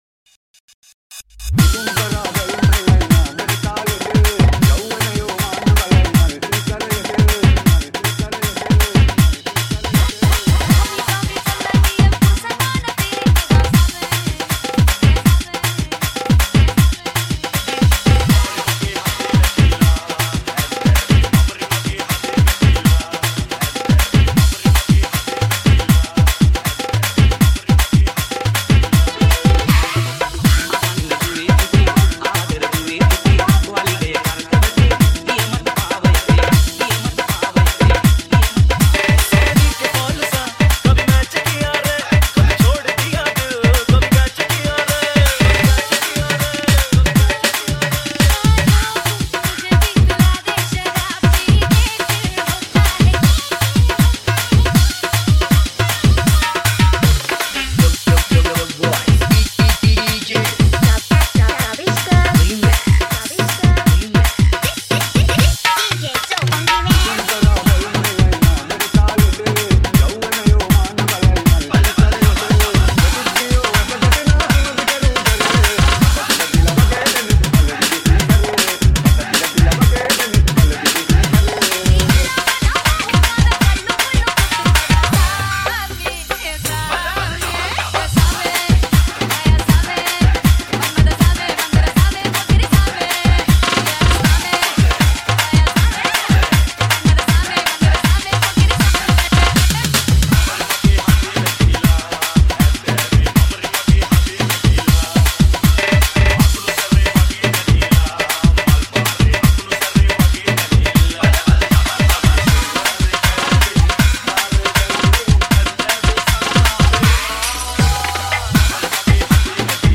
High quality Sri Lankan remix MP3 (5.9).
Remix